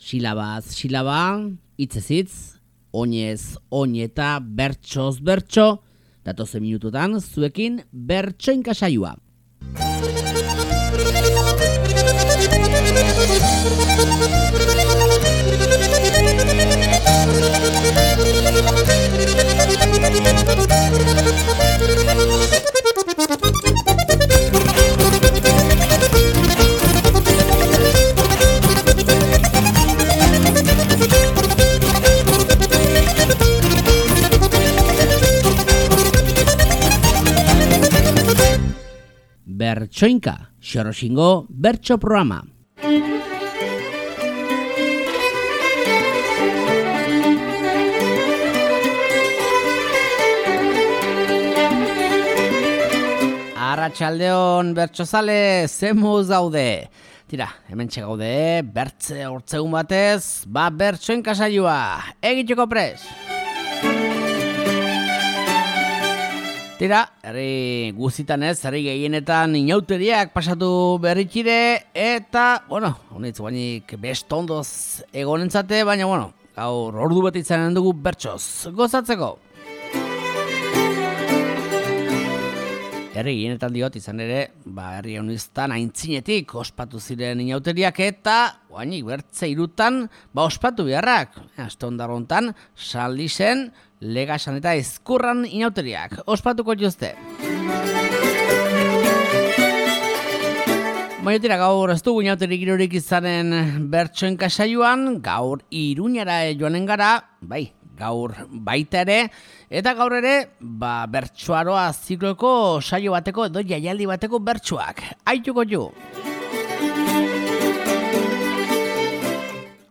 Bertsoaroa zikloaren harira, joan den otsailaren 20an, bertso jaialdia egin zen Iruñean,Bertako aukeraketa bat aste honetako Bertsoinka saioan